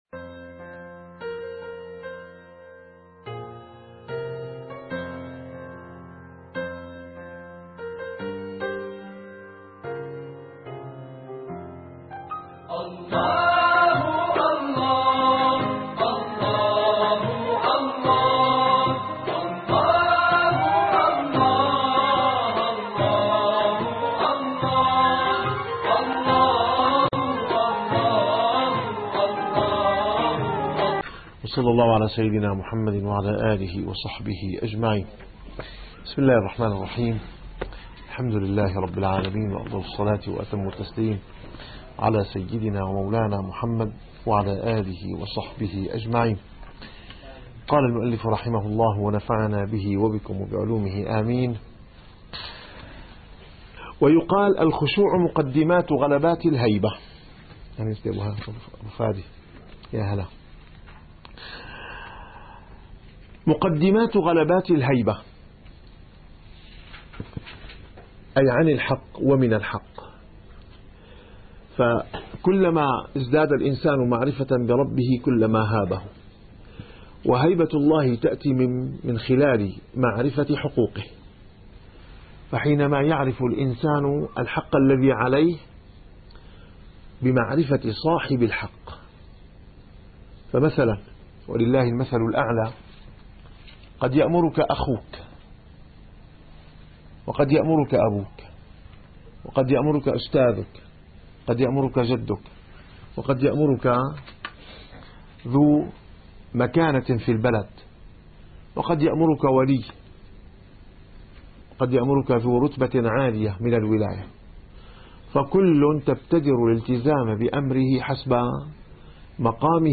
- الدروس العلمية - الرسالة القشيرية - الرسالة القشيرية / الدرس التاسع والعشرون بعد المئة.